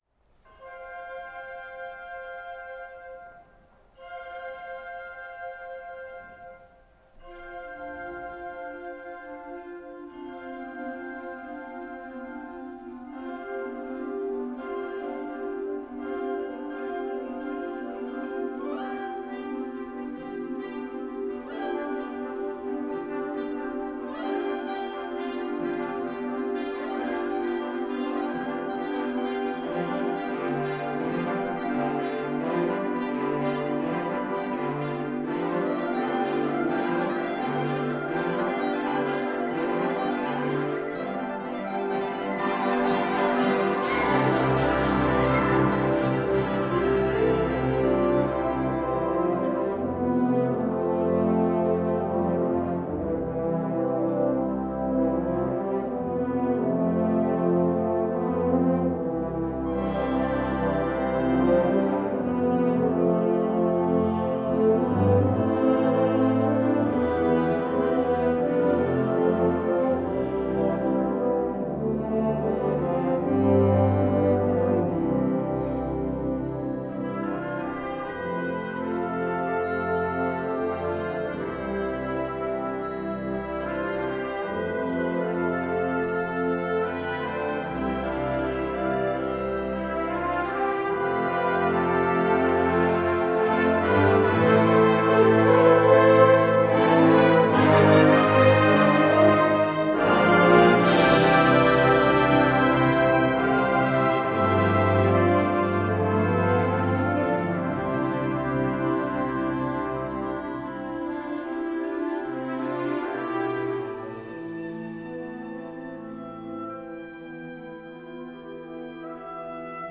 for symphonic wind ensemble